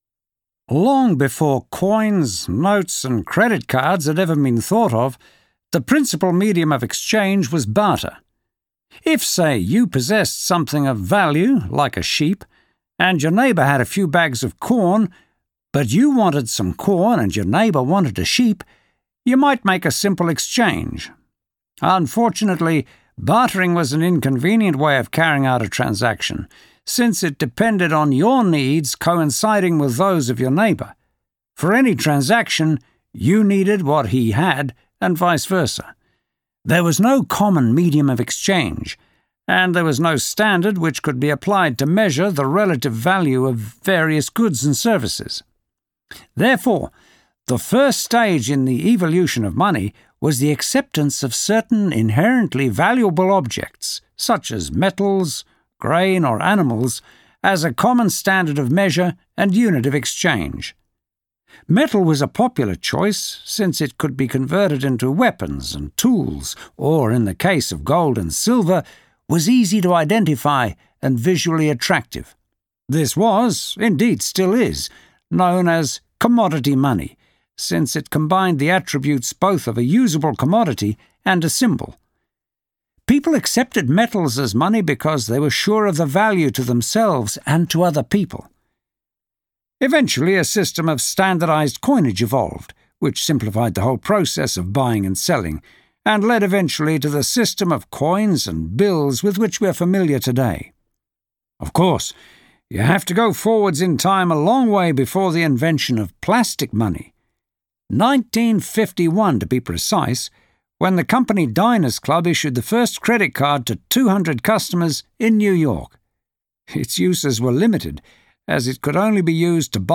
This is part of a lecture on the same topic as the passage that you have just read.